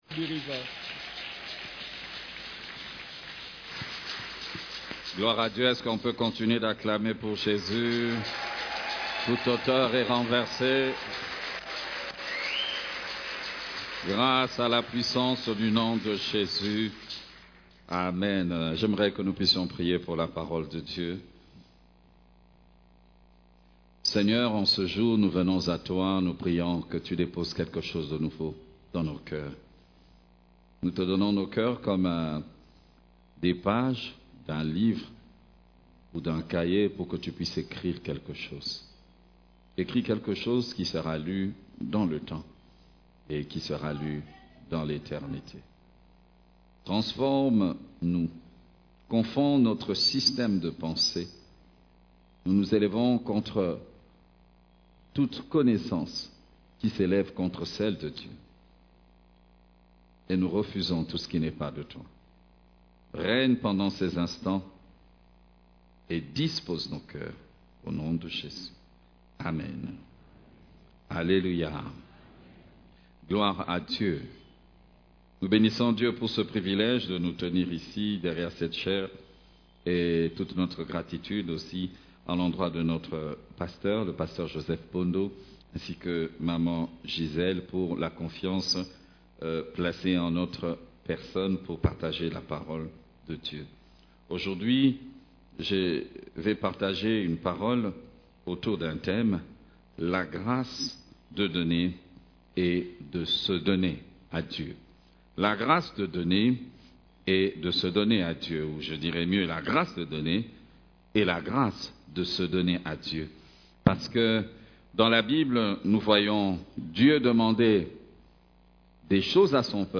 CEF la Borne, Culte du Dimanche, La grâce de donner à Dieu